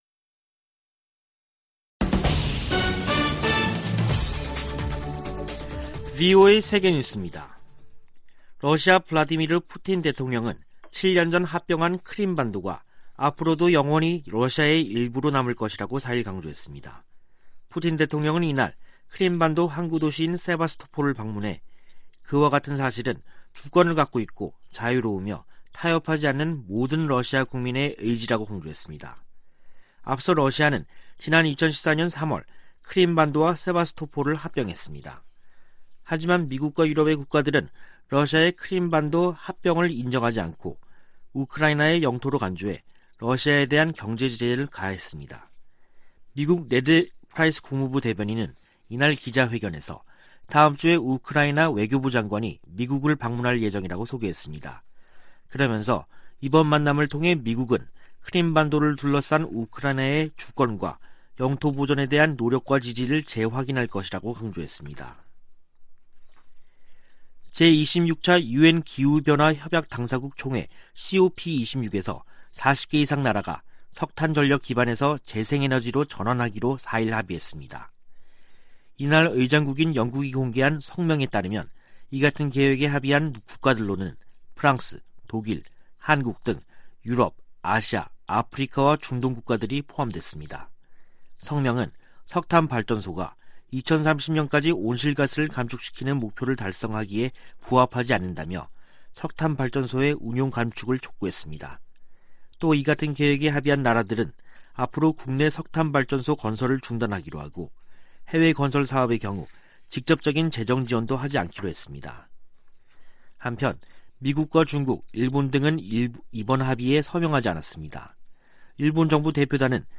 VOA 한국어 간판 뉴스 프로그램 '뉴스 투데이', 2021년 11월 5일 2부 방송입니다. 북한의 불법 무기 프로그램 개발을 막기 위해 전 세계가 유엔 안보리 대북제재를 이행할 것을 미 국무부가 촉구했습니다. 또한 국무부는 북한의 사이버 활동이 전 세계에 위협인 만큼 국제사회 협력이 필수적이라고 밝혔습니다. 뉴질랜드가 안보리 대북제재 위반 활동 감시를 위해 일본 해상에 항공기를 배치합니다.